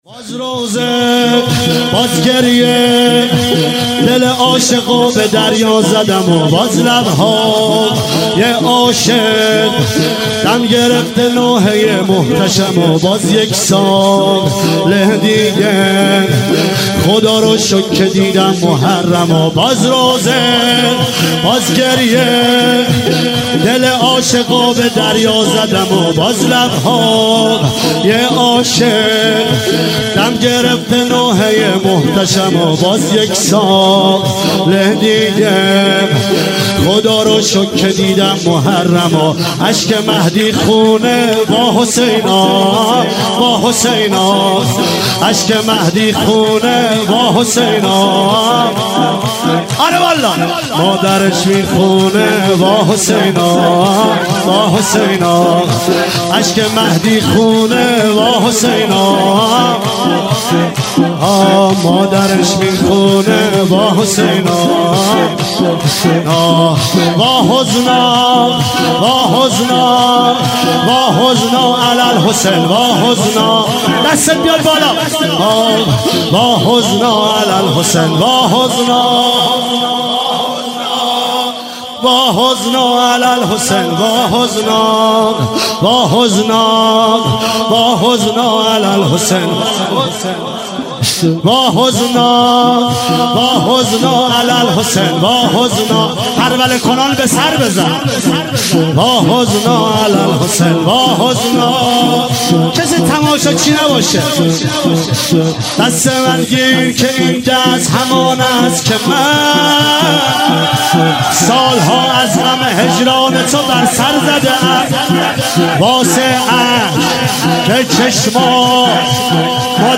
دانلود جدیدترین و گلچین بهترین مداحی های محرم